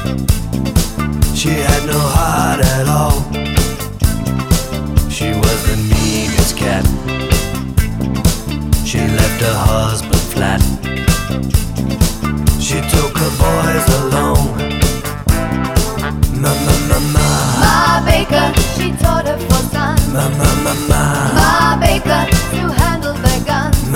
For Duet Disco 4:40 Buy £1.50